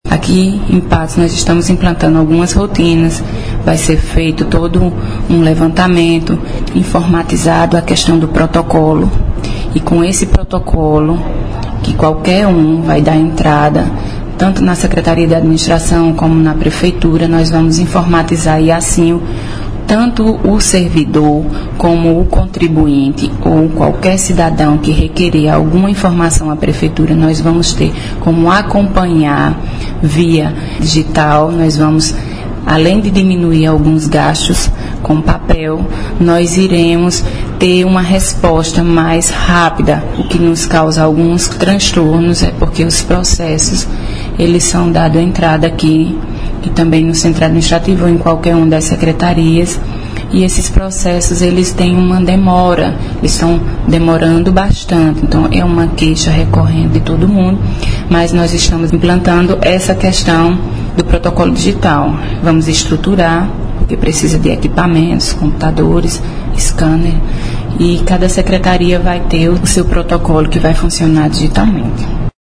Fala da secretária de Controle Interno, Iasnaya Pollianna –